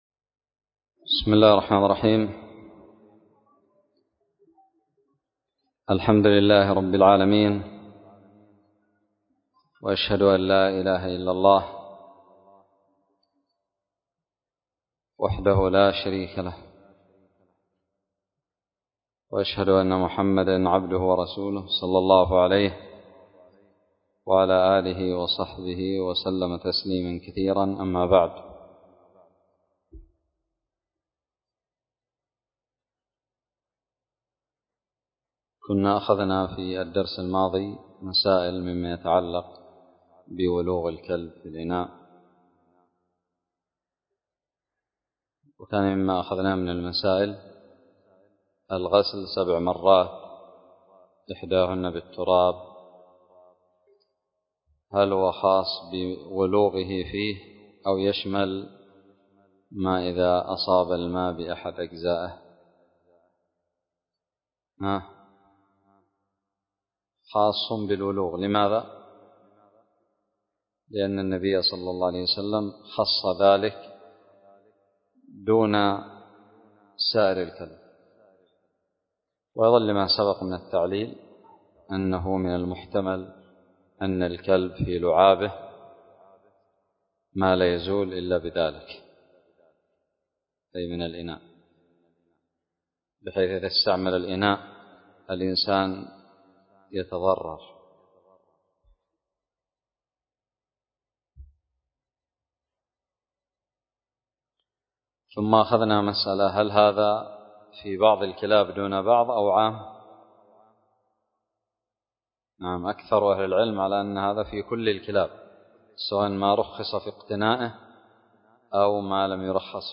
الدرس الثامن عشر من كتاب الطهارة من كتاب المنتقى للمجد ابن تيمية
ألقيت بدار الحديث السلفية للعلوم الشرعية بالضالع